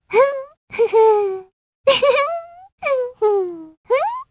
Frey-Vox_Hum_jp.wav